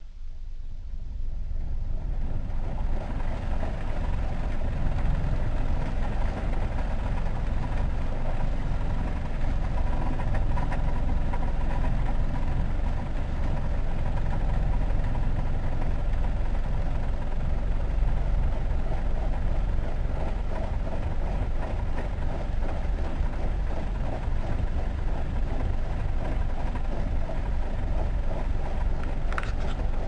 nonmusic files " 车内噪音
描述：记录了工作车辆的声音。
标签： 氛围 噪音 车辆
声道立体声